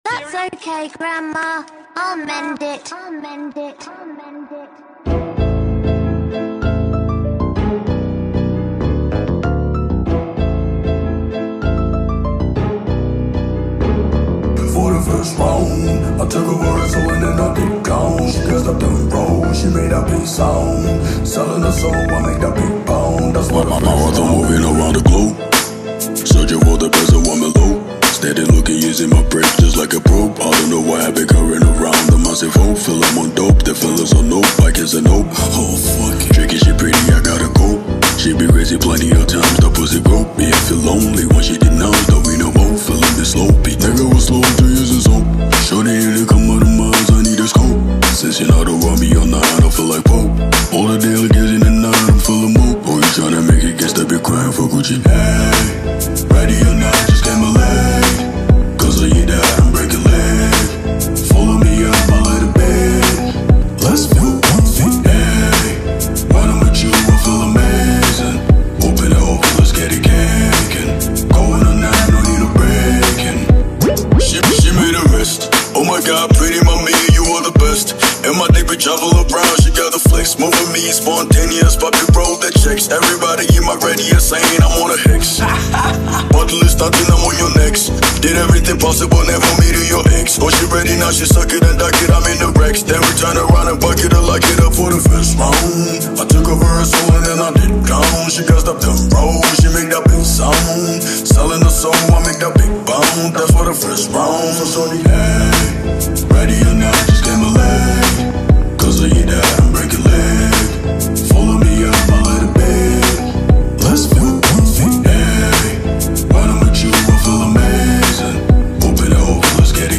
Sensational Ghanaian Hip Pop Artiste
hard-hitting hip pop track
alt-R&B